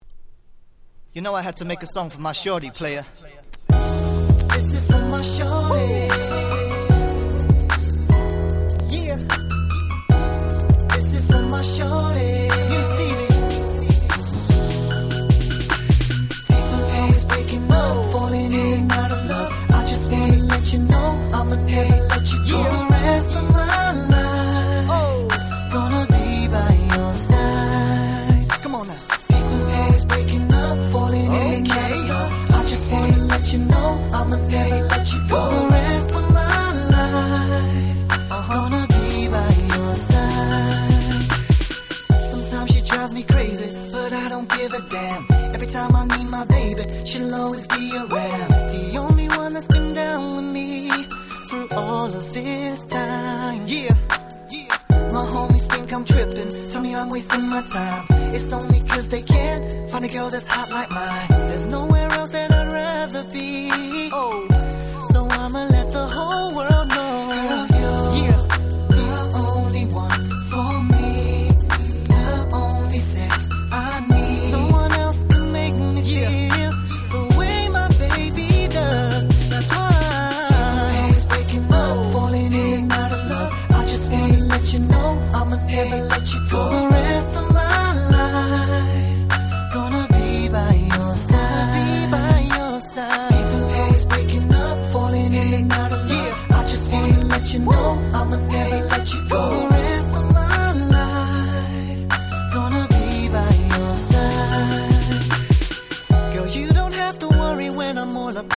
08' 美メロR&B♩
思い切り一般受けしそうな美メロ&Smoothのキラキラな2曲！